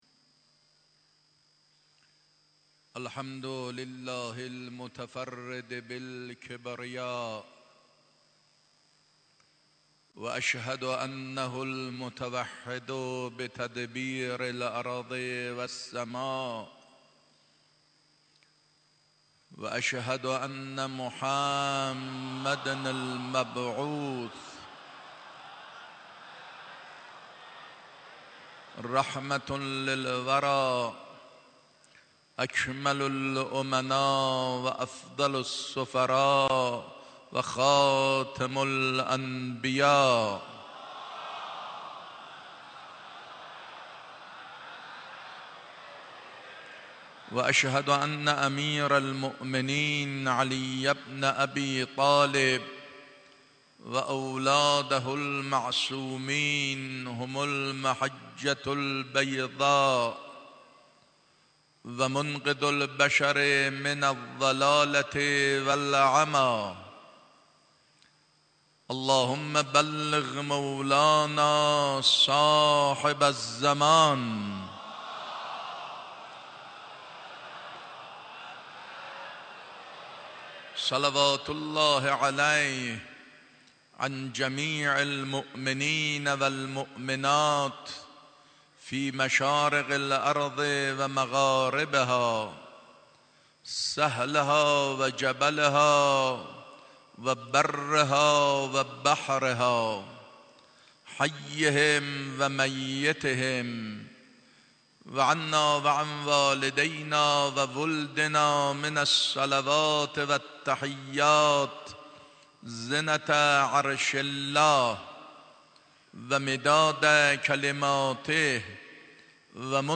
خطبه اول.lite.mp3
خطبه-اول.lite.mp3